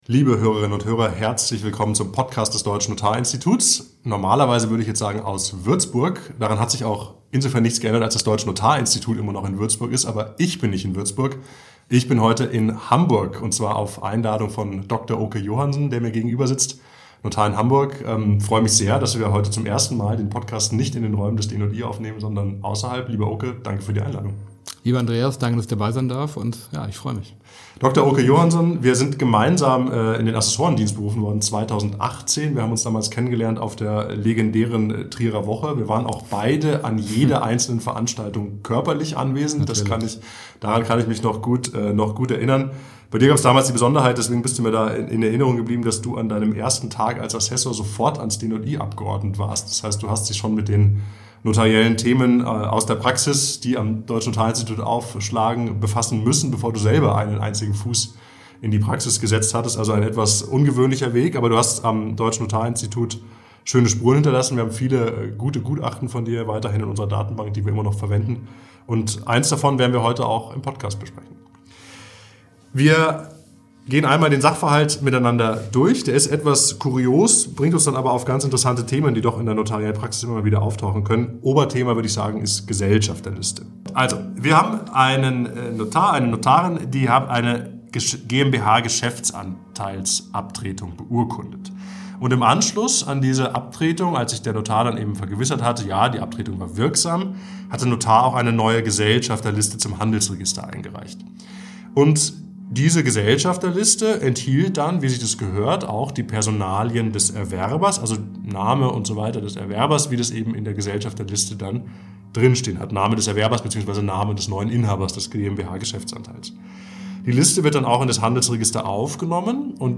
Nach der Beurkundung einer Geschäftsanteilsabtretung stellt sich heraus, dass der Erwerber über seine wahre Identität hinweggetäuscht hatte. Was hat der Notar nun im Hinblick auf die Gesellschafterliste zu veranlassen? (Im Hintergrund läuteten übrigens die Glocken der Kirche St. Petri)